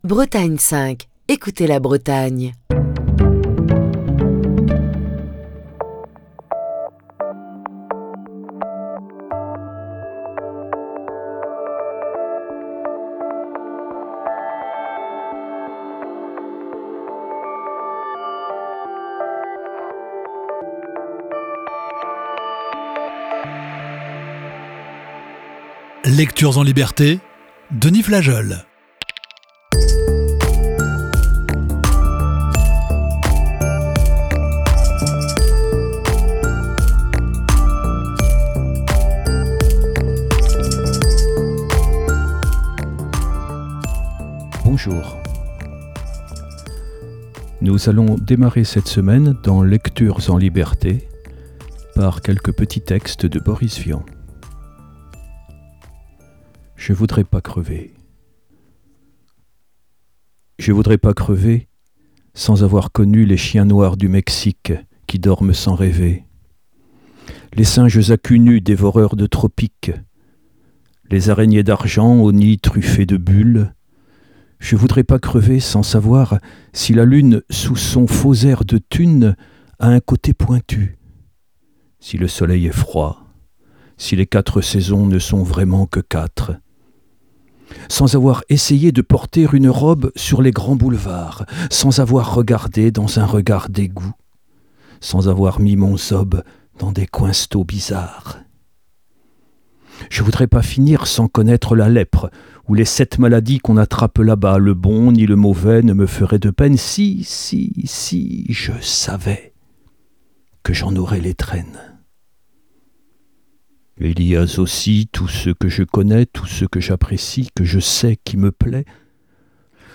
Émission du 11 septembre 2023.